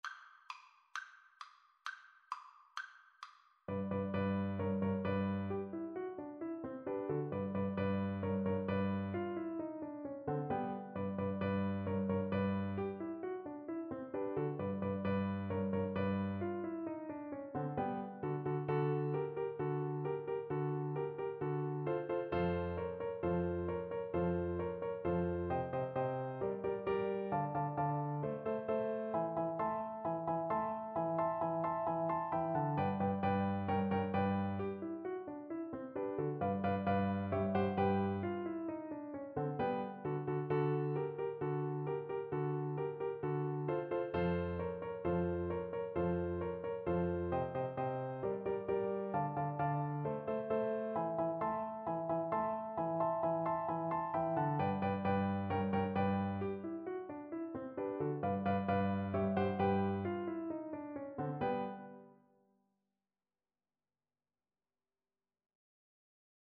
2/4 (View more 2/4 Music)
Classical (View more Classical Flute Music)